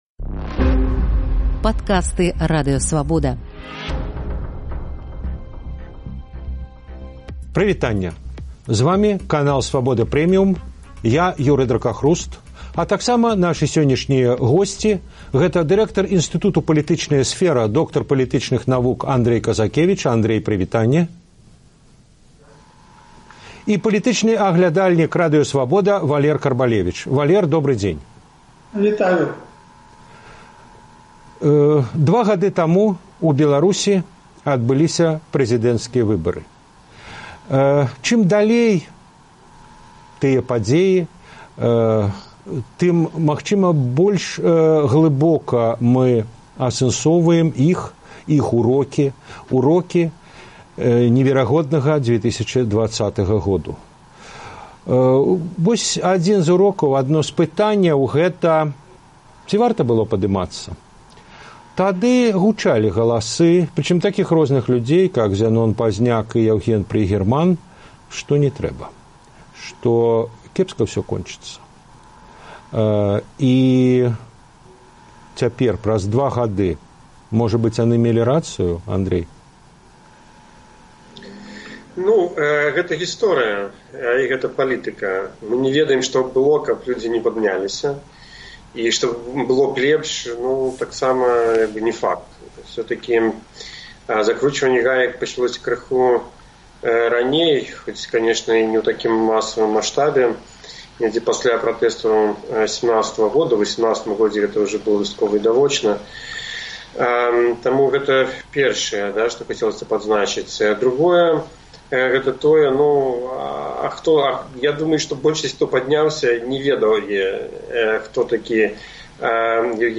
Ці варта было паўставаць? Дыскусія аналітыкаў